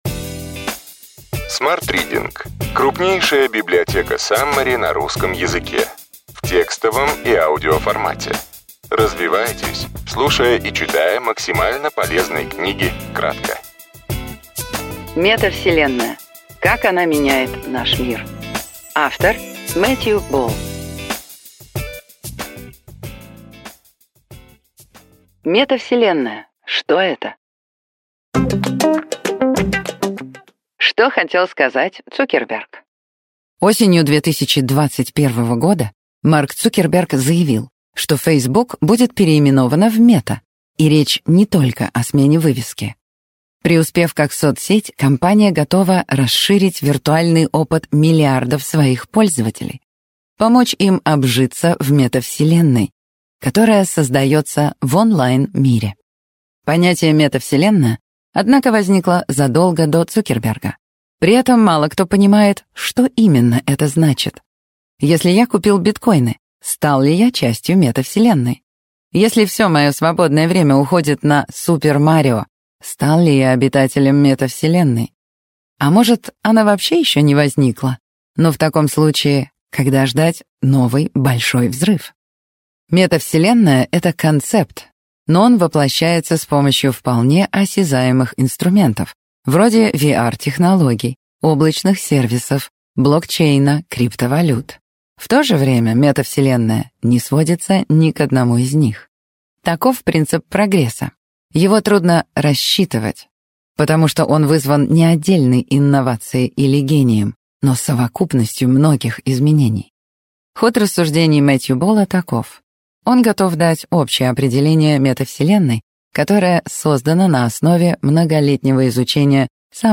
Аудиокнига Метавселенная. Как она меняет наш мир.